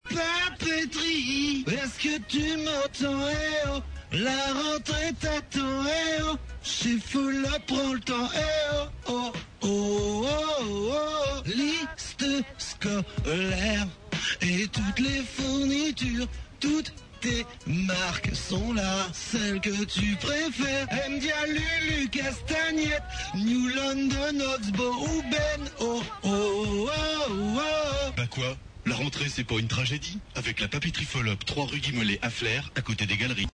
Pub radio